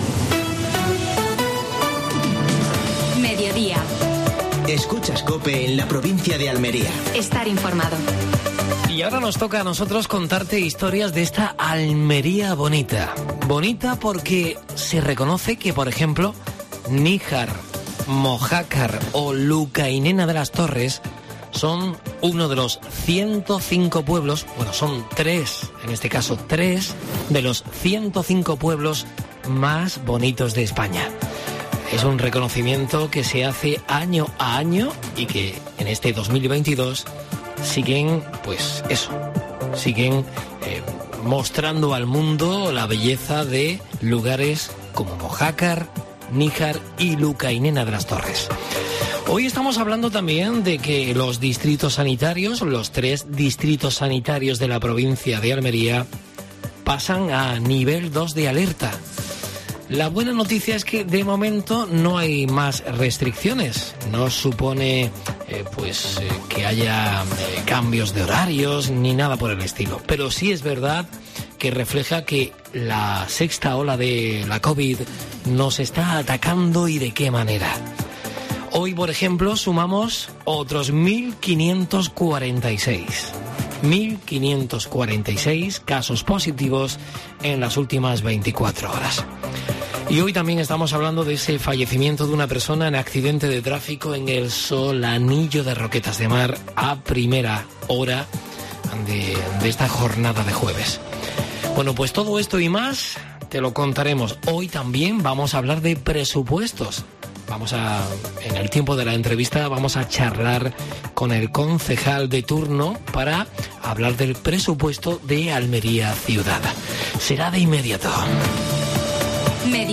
AUDIO: Actualidad en Almería. Entrevista a Juanjo Alonso (concejal de Economía del Ayuntamiento de Almería). Última hora deportiva.